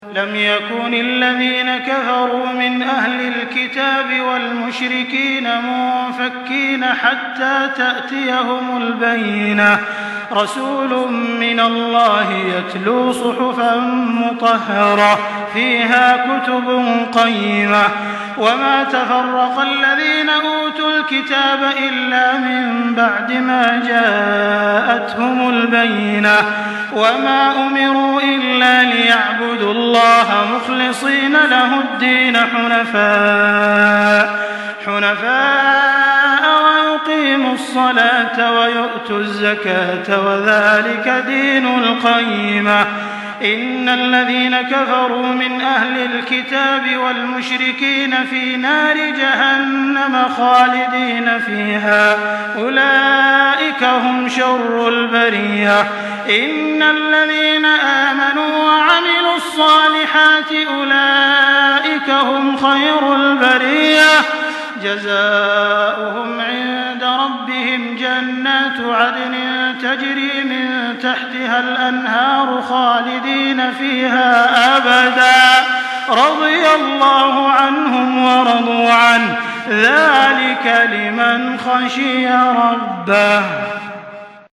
Makkah Taraweeh 1424
Murattal